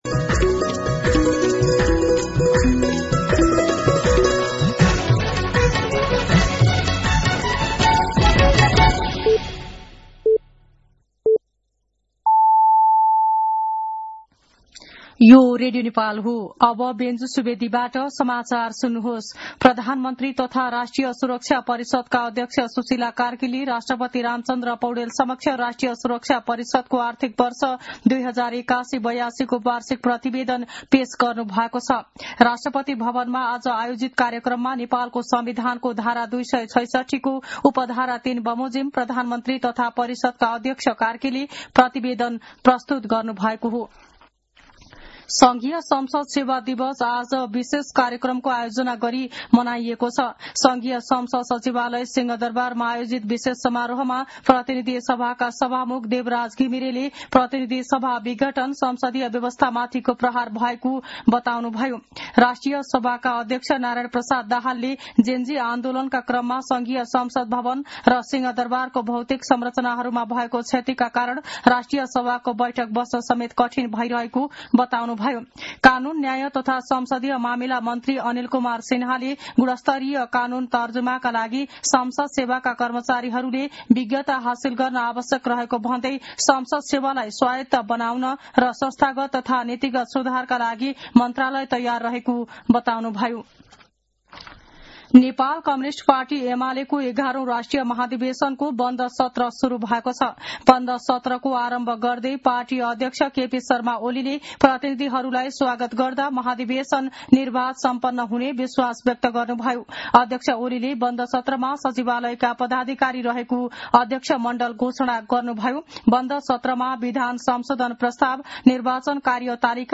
साँझ ५ बजेको नेपाली समाचार : २८ मंसिर , २०८२
5-pm-news-.mp3